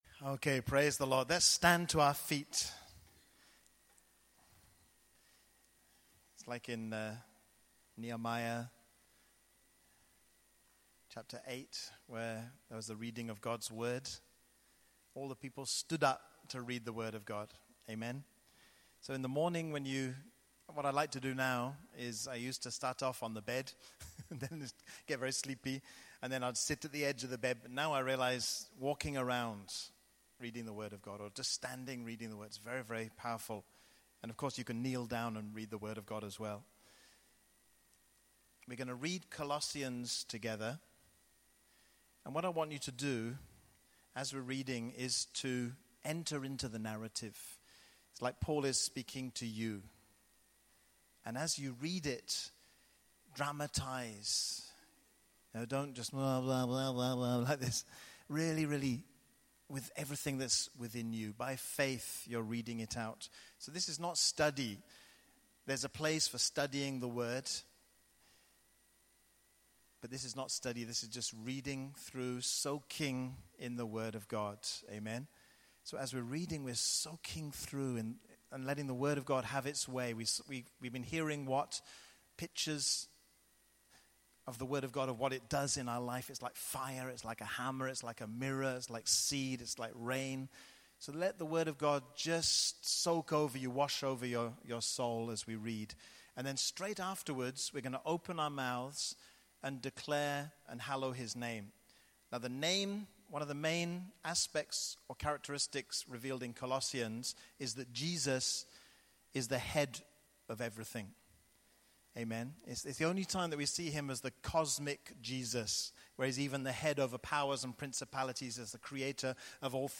See also Tabernacle Tour Practical Session: Prayer Altar Experience. Click above audio button to experience one hour l ive in Grace Methodist Church Feb 2017.